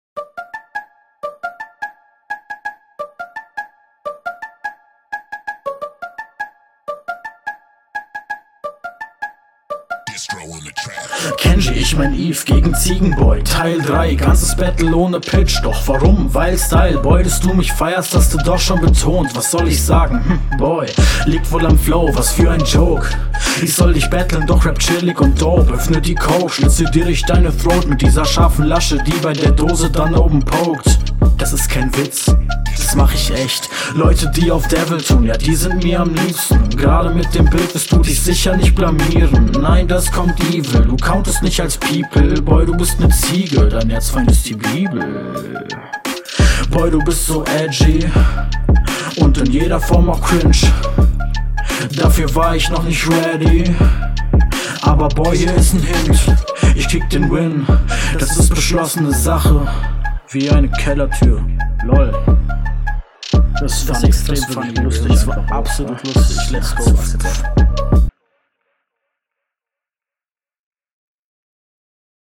Flow: flow wie vorher auch schon deine stärke, hier rappst du halt nur gechillt, wie …
Flow: solide, aber find ich insgesamt recht langweilig Text: die reimplatzierung bei der Ou-Reimkette war …